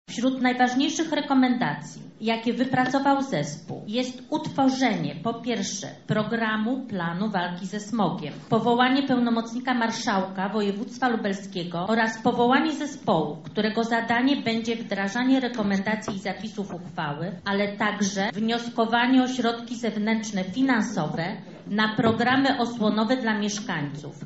Na Sesji skierowana została również interpelacja o powołanie komisji do pracy nad uchwałą antysmogową. O jego przyjęcie wnioskowała radna Bożena Lisowska:
III Sesja Sejmiku Województwa